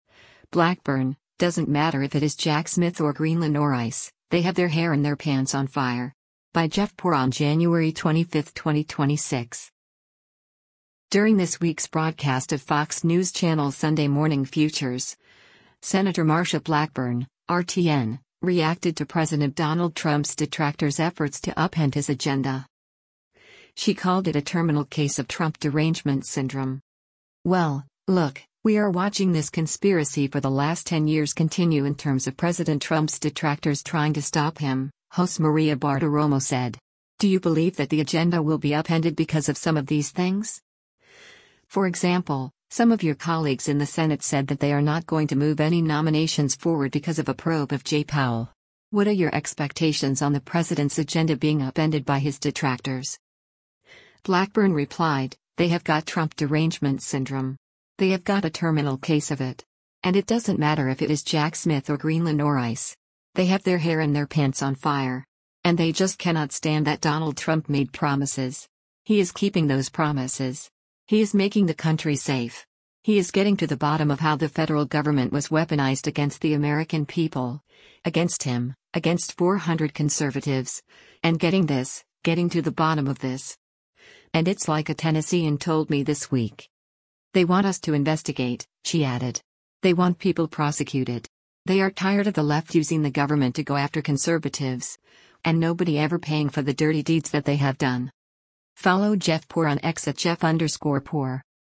During this week’s broadcast of Fox News Channel’s “Sunday Morning Futures,” Sen. Marsha Blackburn (R-TN) reacted to President Donald Trump’s detractors’ efforts to “upend” his agenda.